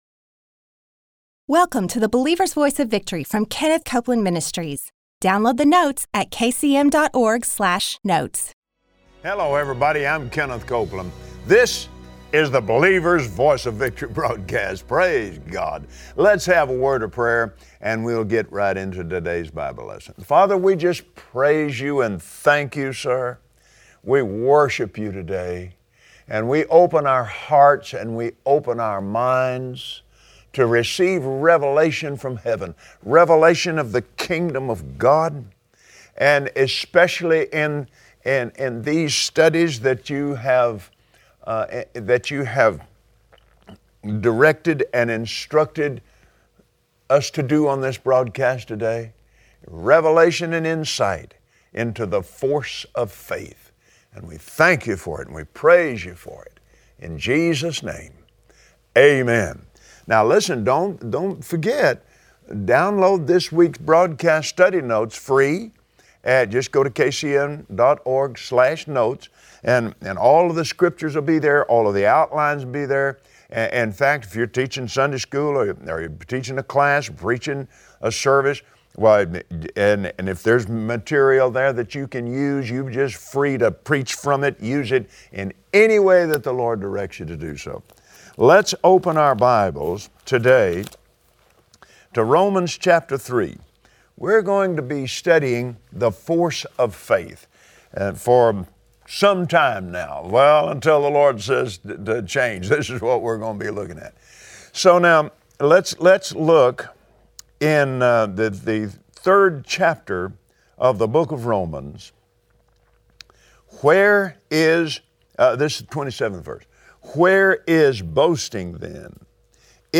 Believers Voice of Victory Audio Broadcast for Monday 03/14/2016Today Kenneth Copeland begins a foundational teaching on the force of faith. Learn where faith begins, why it works and how it is released.